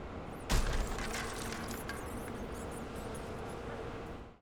环境音